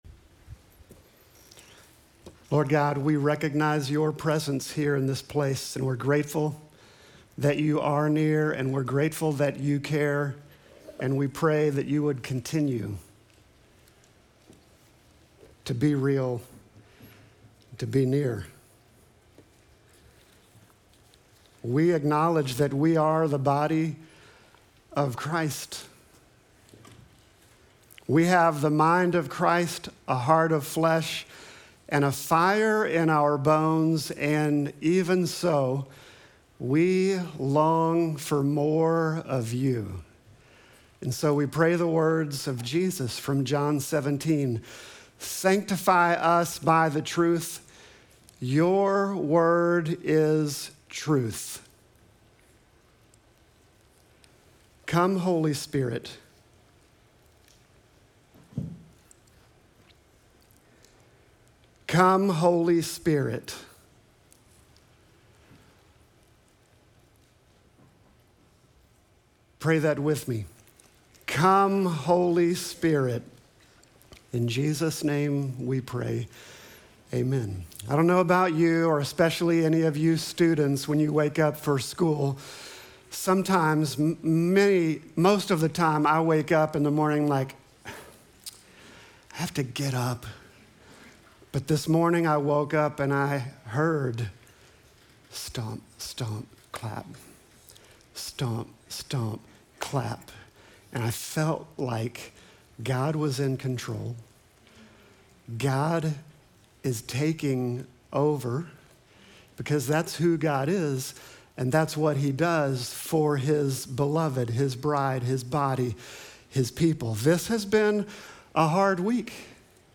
Sermon text: Acts 4:32-35